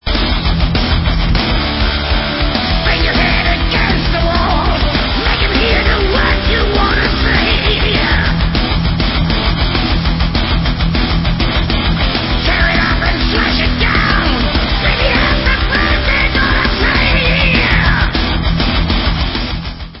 Heavy Metal